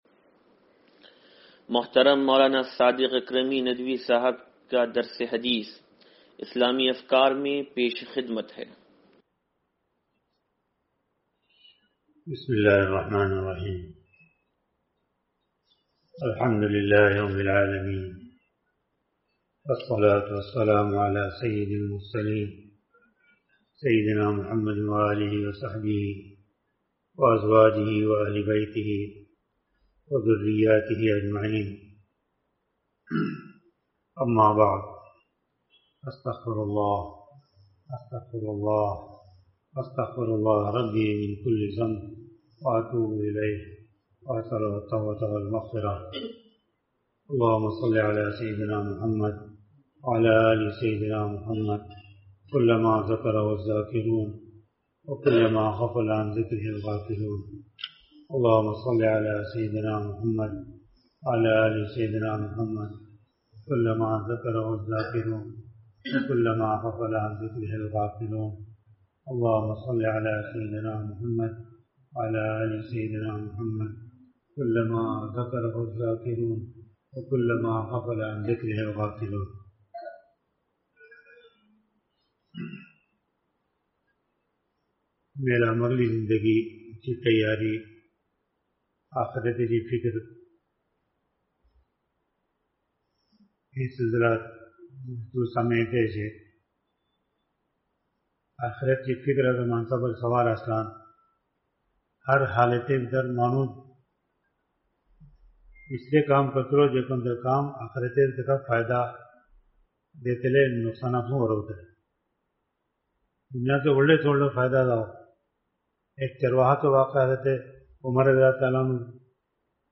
درس حدیث نمبر 0592